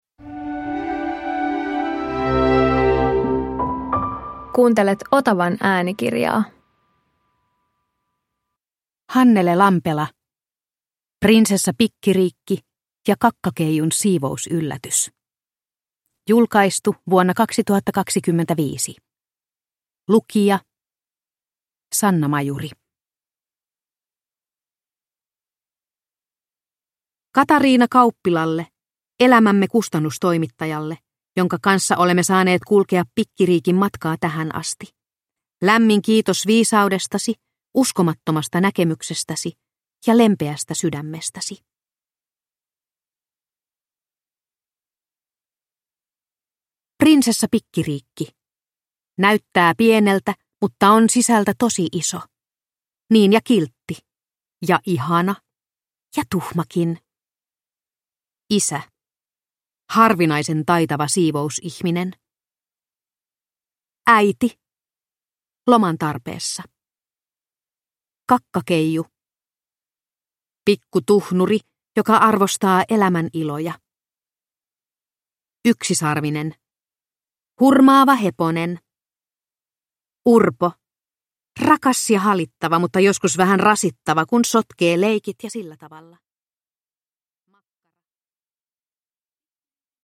Prinsessa Pikkiriikki ja Kakkakeijun siivousyllätys (ljudbok) av Hannele Lampela